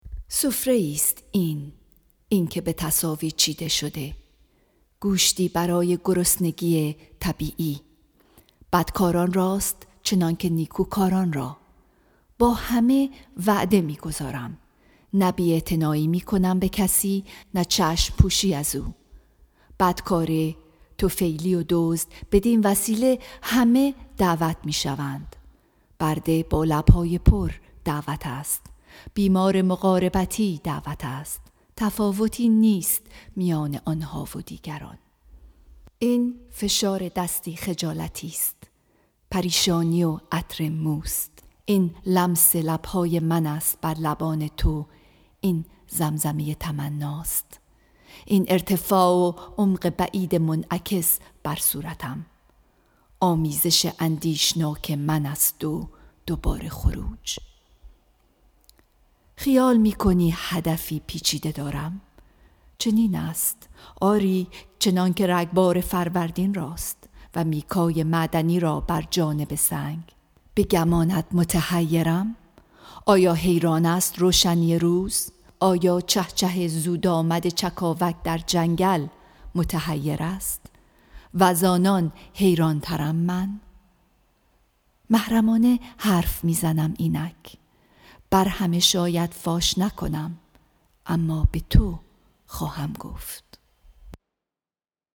Song of Myself, Section 19 —poem read